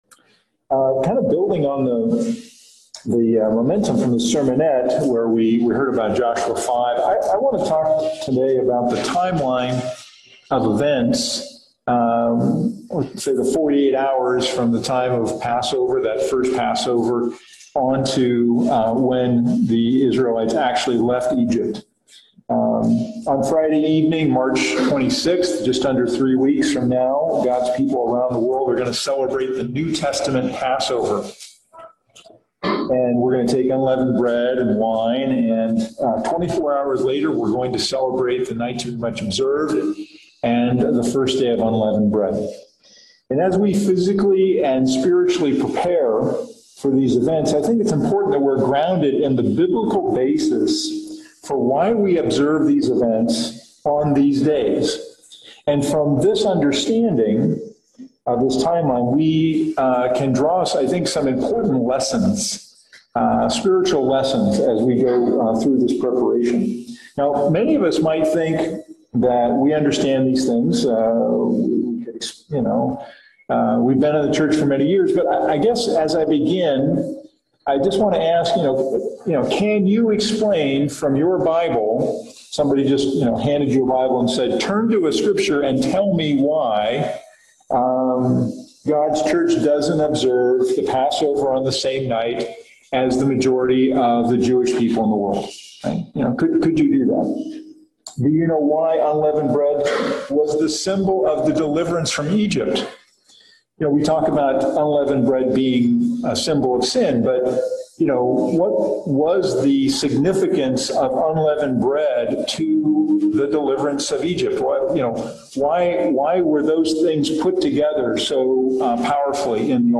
3/6/21 Why does God’s Church keep the Passover on a different night than Judaism and what was the timeline of events for the Ancient Israelites from the beginning of Passover to the exiting of Egypt? In this sermon